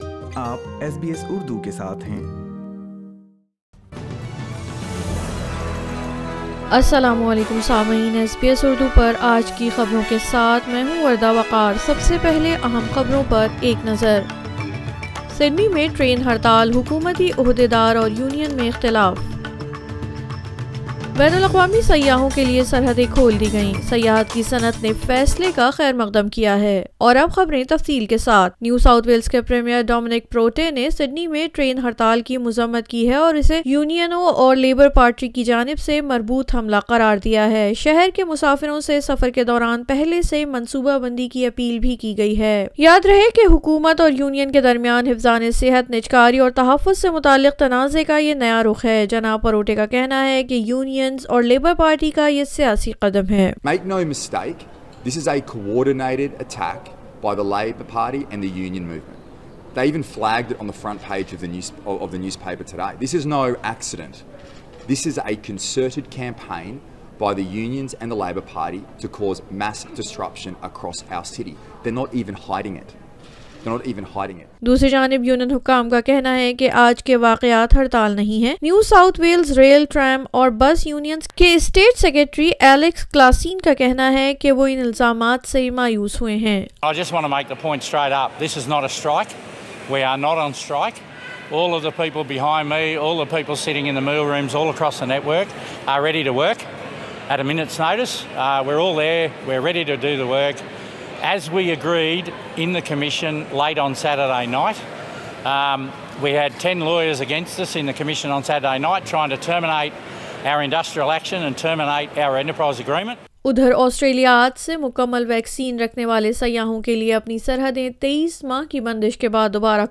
A city-wide train strike in Sydney has government and union officials at odds. Members of the tourism industry welcome the re-opening of Australia's borders to international tourists. For more details listen to Urdu news